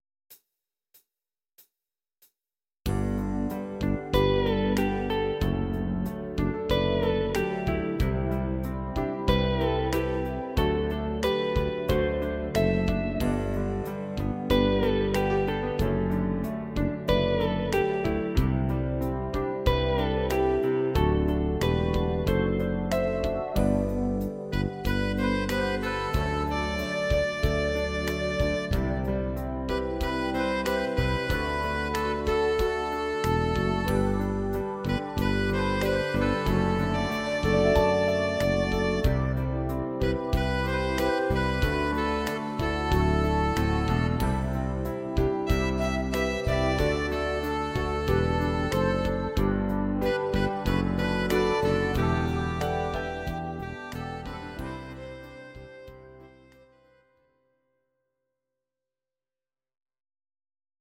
Please note: no vocals and no karaoke included.
Your-Mix: Instrumental (2069)